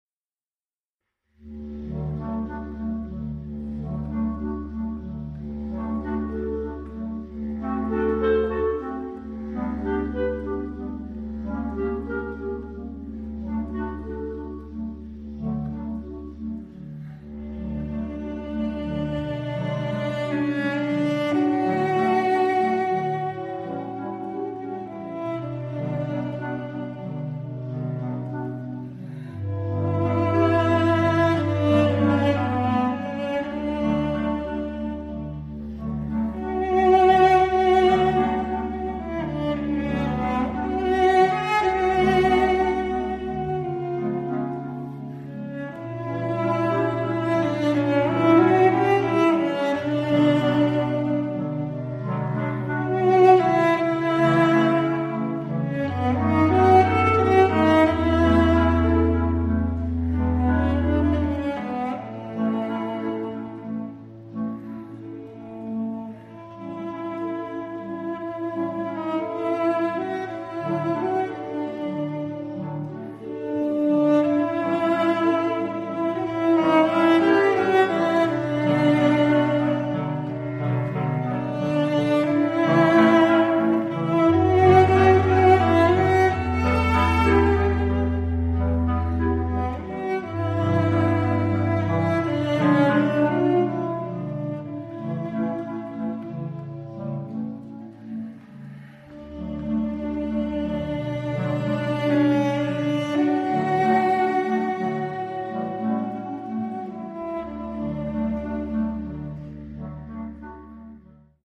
Picc Clarinette
Baset Horn
Bass Clarinette
Percussion